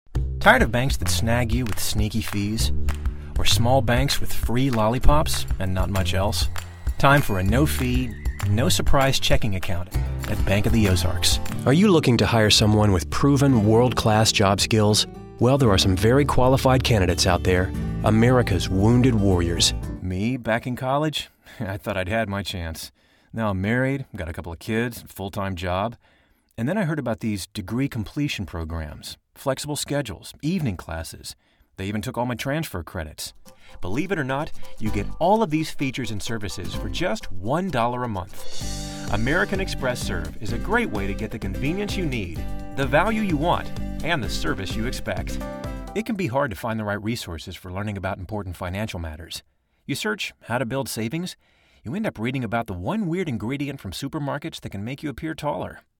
Adult
Has Own Studio
southern us